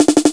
drumbam.mp3